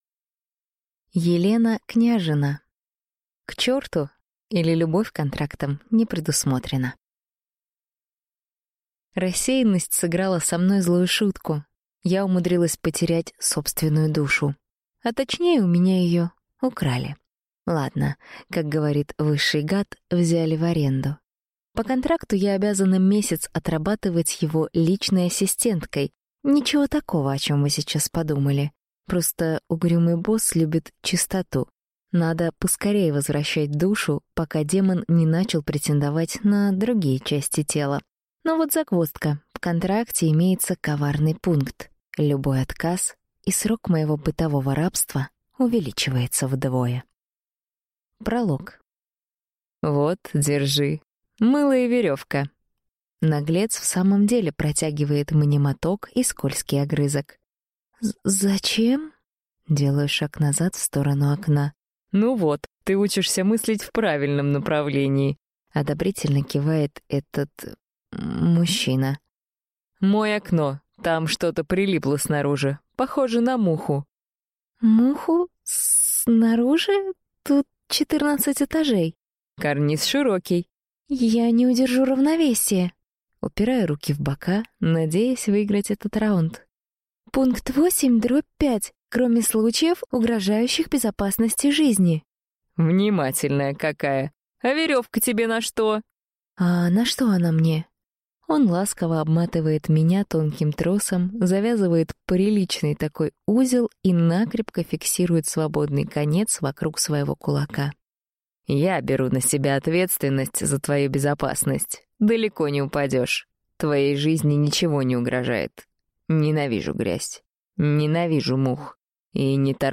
Аудиокнига К черту! или Любовь контрактом не предусмотрена | Библиотека аудиокниг